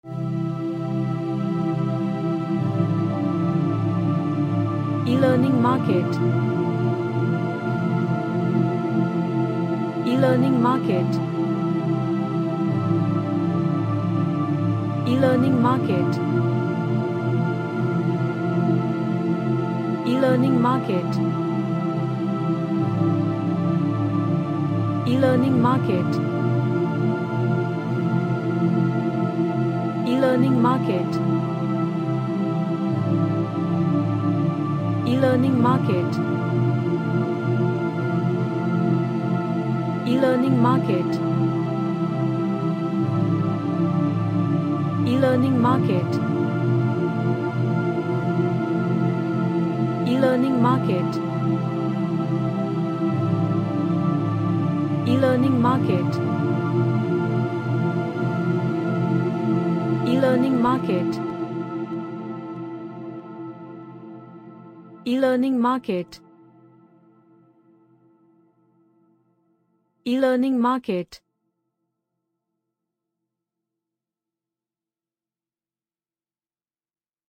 An Ambient track with lots of pads and relaxing vibe.
Relaxation / Meditation